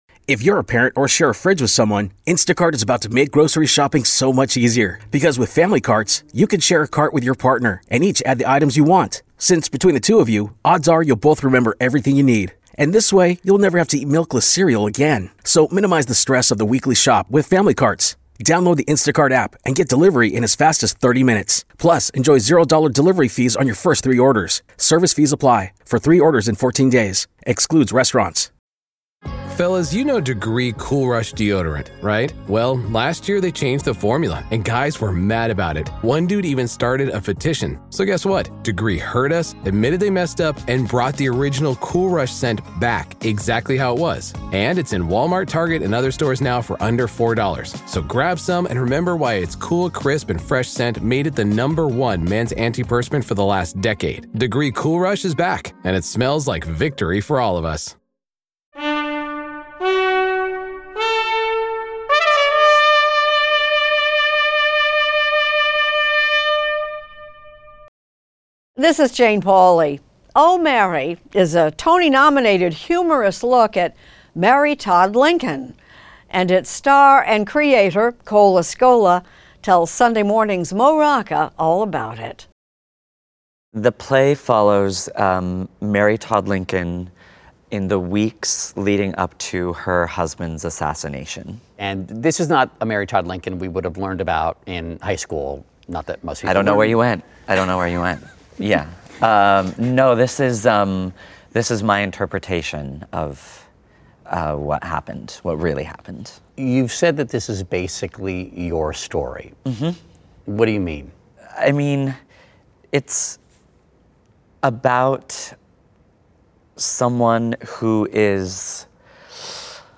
Extended Interview: "Oh, Mary!" Tony Nominee Cole Escola
Actor and playwright Cole Escola talks with correspondent Mo Rocca about the genesis of the Broadway farce "Oh, Mary!," the over-the-top comedy about Mary Todd Lincoln and her secret passion of becoming a cabaret star, for which Escola earned two Tony Award nominations. Escola also discusses their childhood in Oregon and early experiences in New York City, and gives Rocca a tour of their bespoke-decorated dressing room.